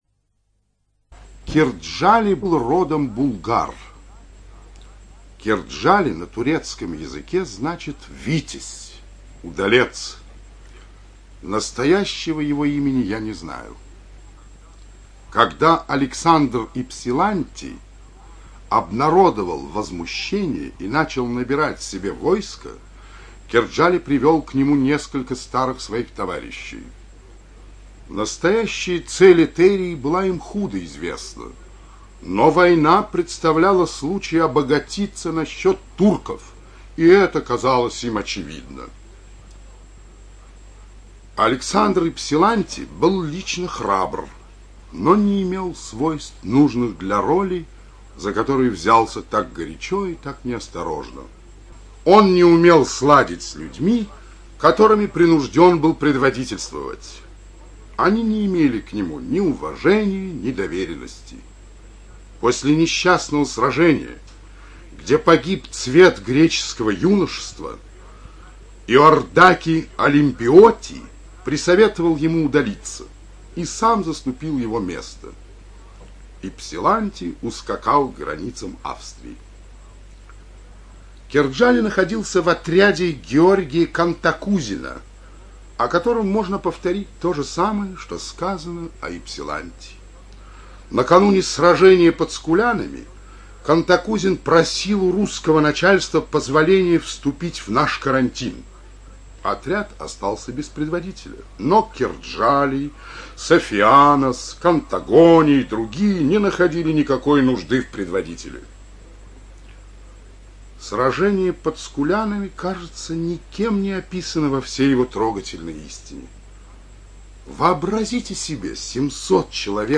ЧитаетЛиванов Б.